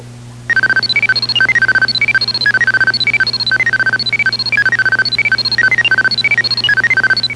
2-XL Computer Sound #01 159k